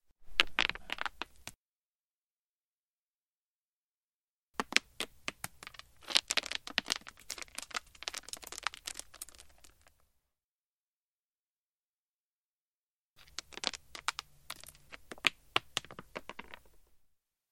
На этой странице собраны звуки камнепада — от легкого шелеста скатывающихся камешков до грохота крупных обвалов.
Звуковая серия: мелкие камни падают с обрыва